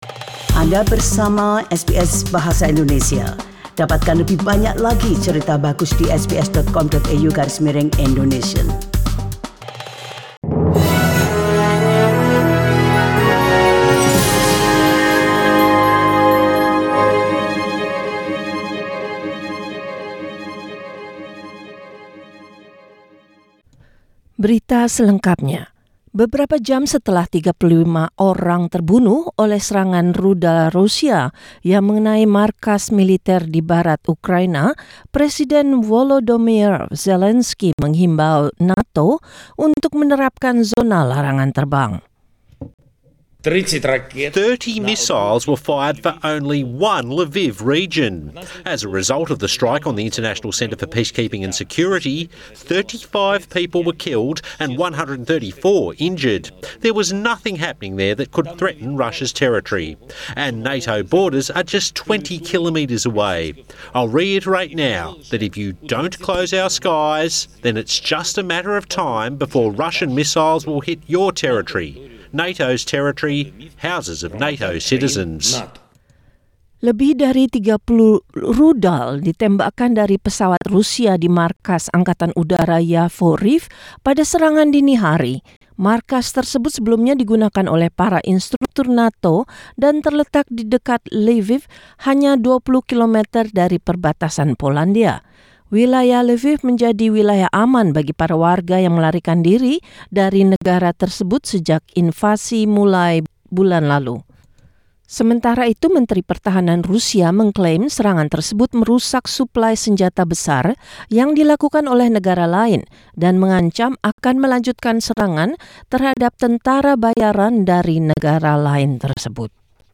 SBS Radio News in Indonesian - Monday, 14 March 2022
Warta Berita Radio SBS Program Bahasa Indonesia.